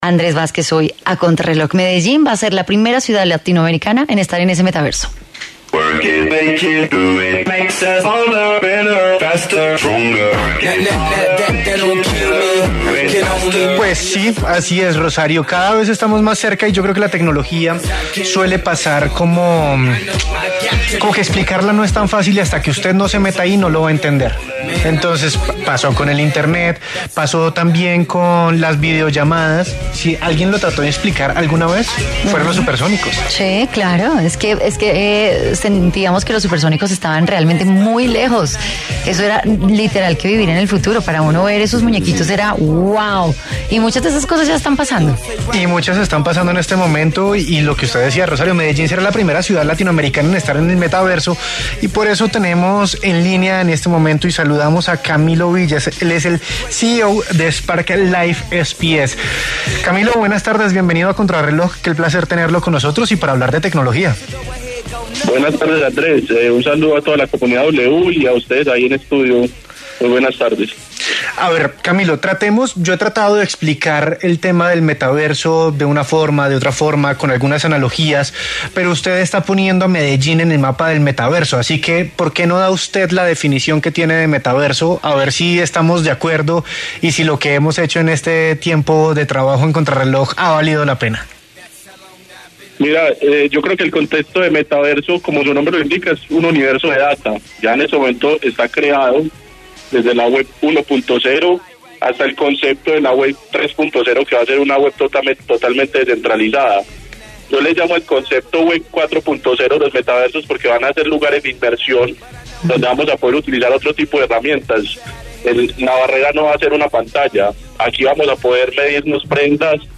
En diálogo con Contrarreloj de W Radio